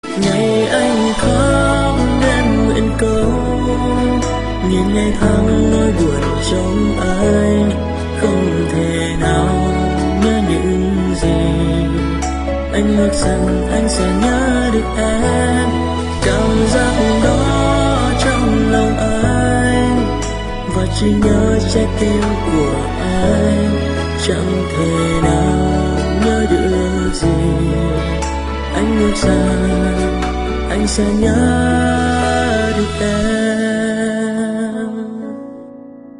Nhạc Trẻ